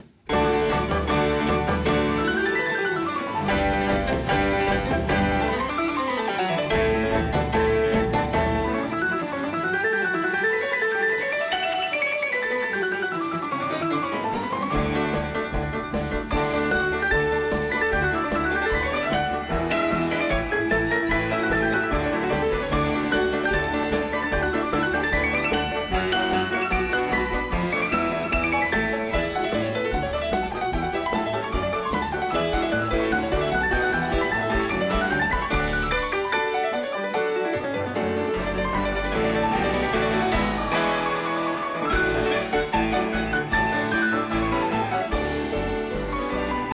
• Качество: 320, Stereo
без слов
инструментальные
пианино
на нескольких фортепиано